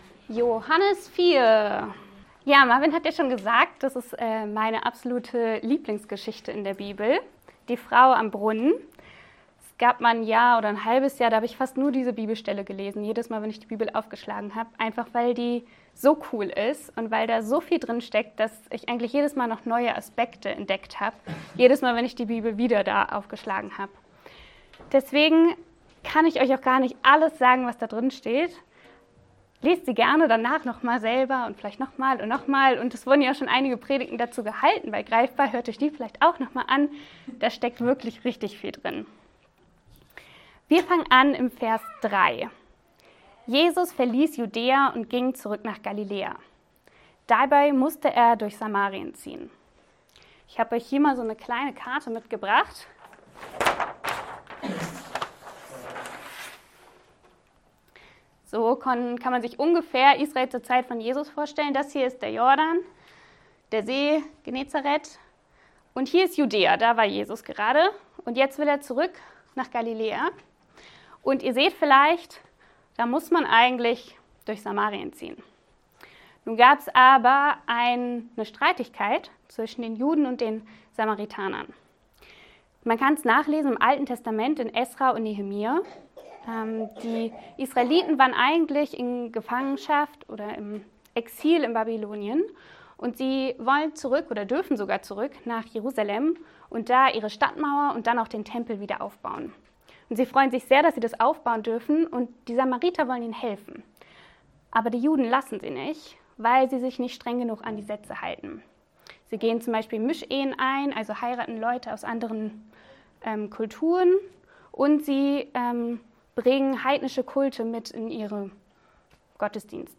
Predigtpodcast